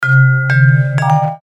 без слов
колокольчики
звонкие
Звон, как при ударе по бутылочкам